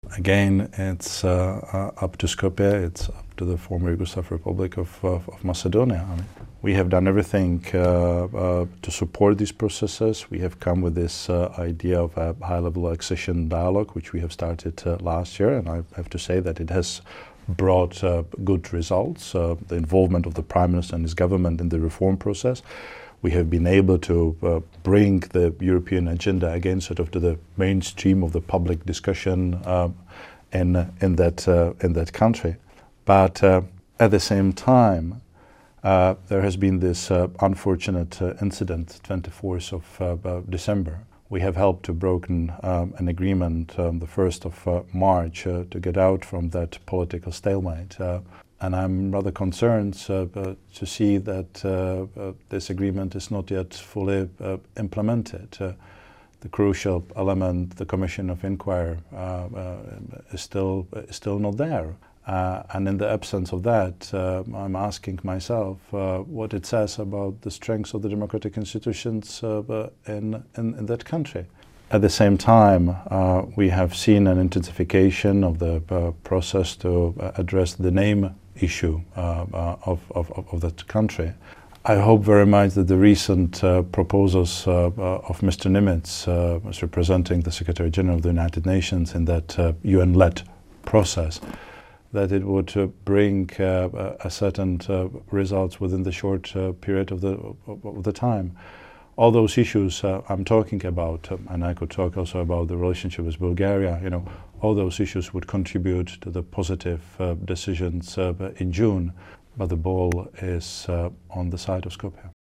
АУДИО: Интервју на Филе за РСЕ
Се надевам дека предлогот на Нимиц ќе даде резултат, изјави еврокомесарот за проширување Штефан Филе во интервју за Радио Слободна Европа, во кое изразува разочараност од сè уште невоспоставената Комисија за настаните од 24 декември.